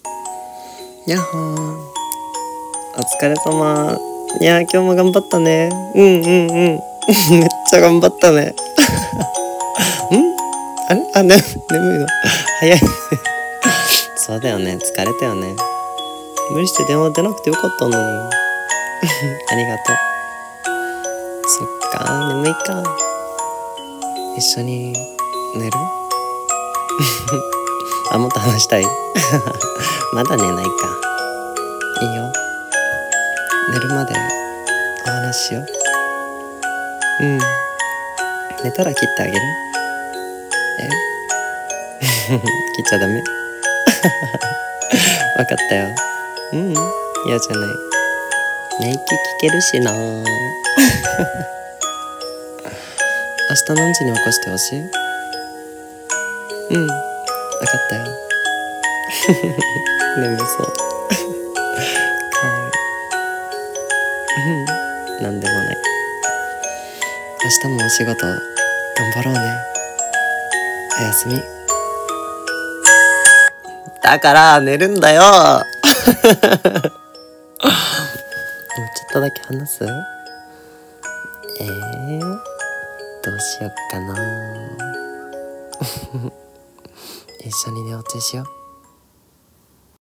【1人用声劇台本】